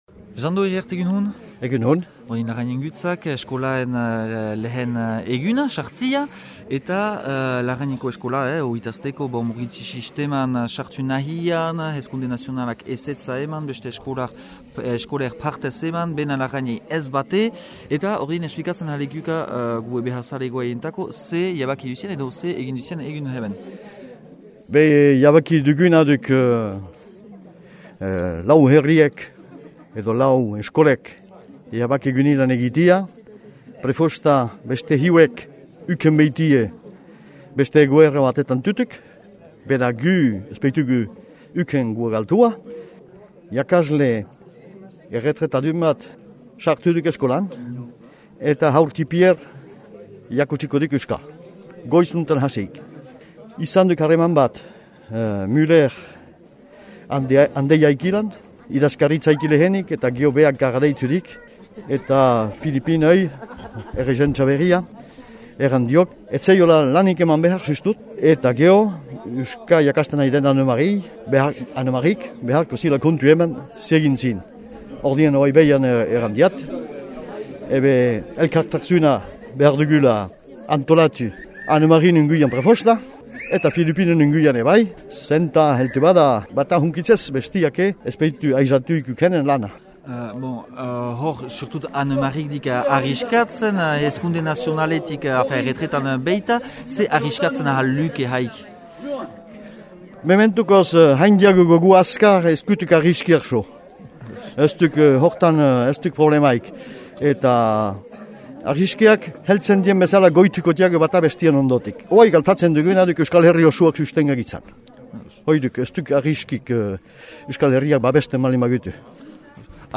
eskolako aitetamak beha ditzagün :